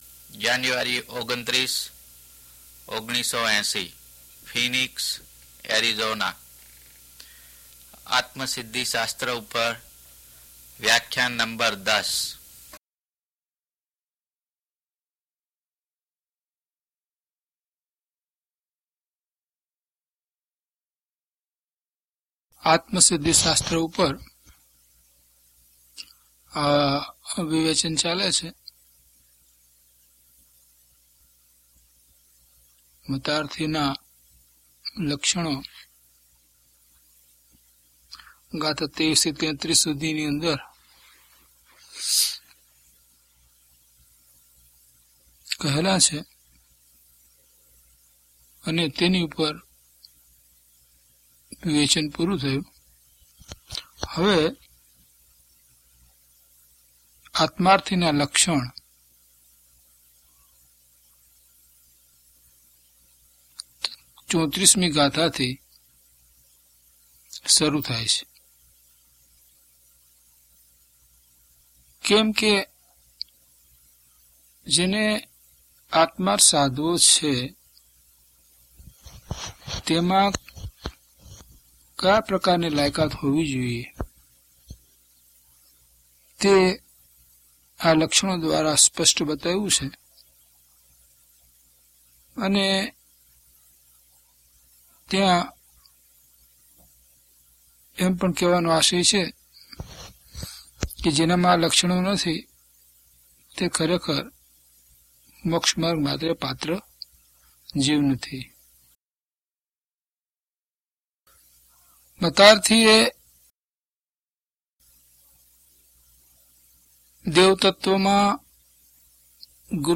DHP019 Atmasiddhi Vivechan 10 - Pravachan.mp3